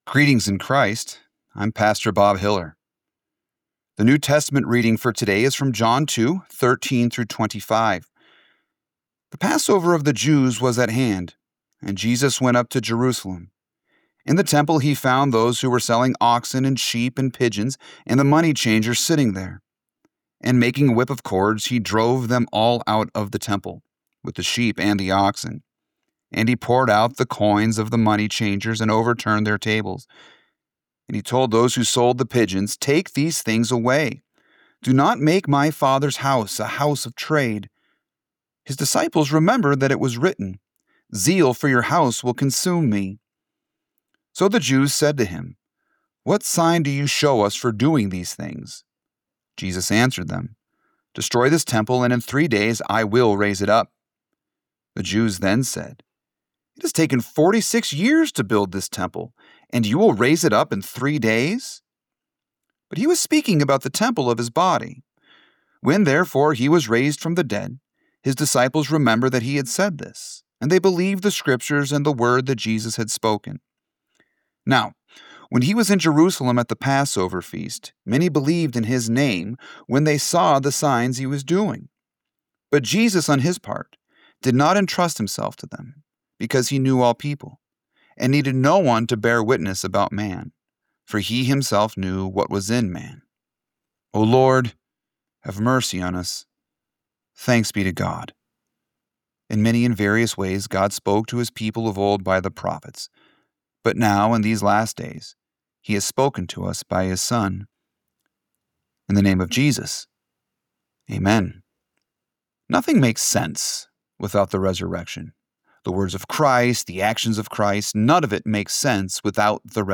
Morning Prayer Sermonette: John 2:13-25